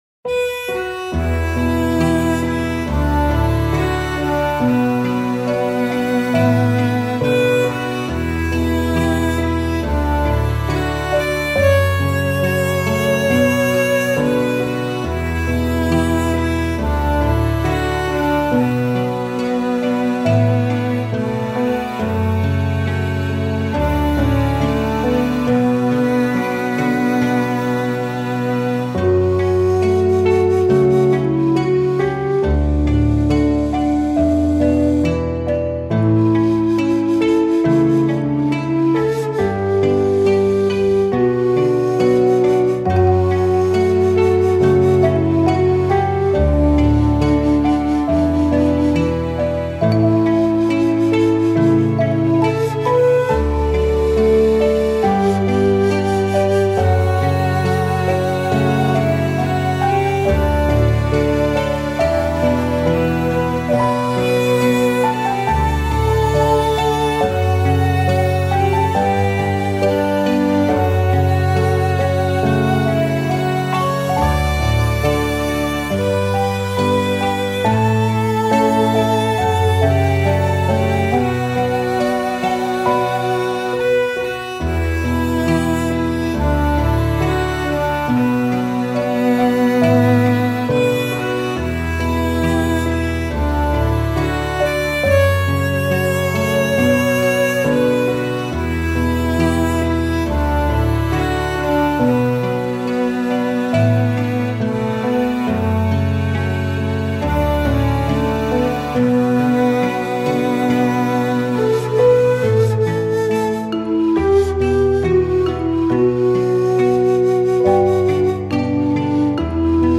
ちょっぴり寂しい雰囲気のある、バイオリンメインのBGMです。